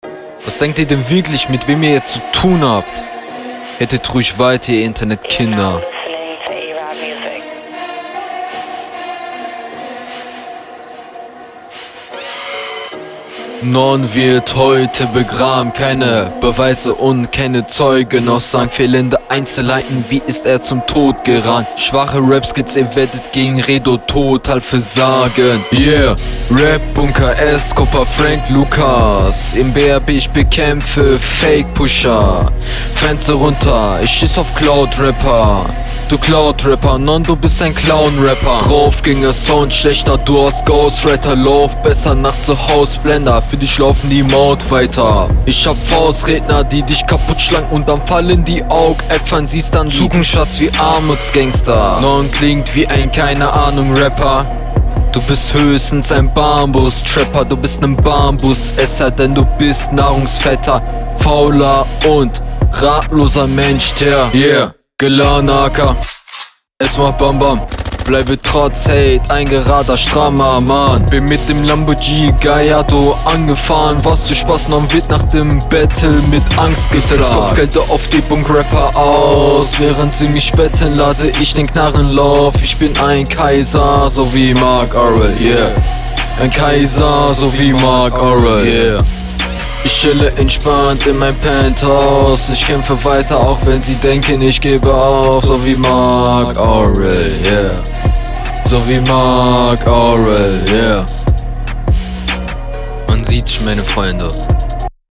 junge die reime sind furchtbar der flow könnte schlechter sein aber is auch weit entfernt …
Anfang leider bisschen offbeat. Aber den Flow danach finde ich eigentlich ganz cool.